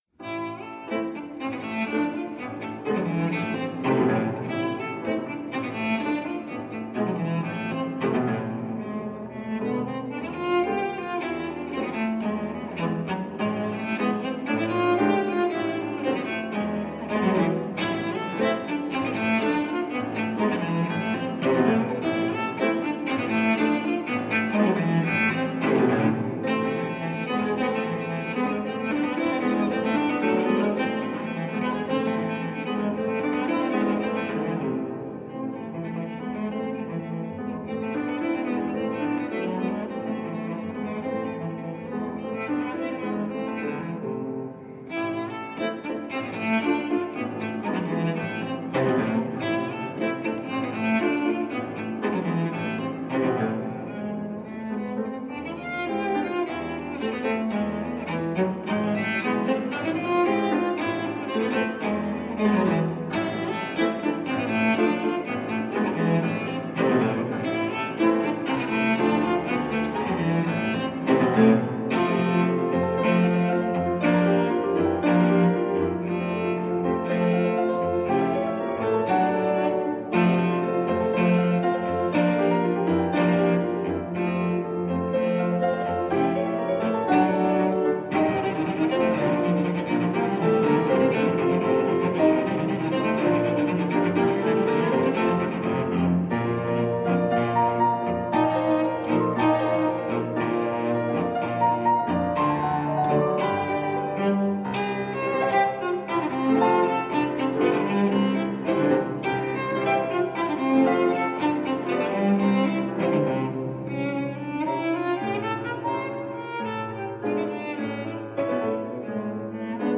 piano
cello | live, Arnold Schoenberg zaal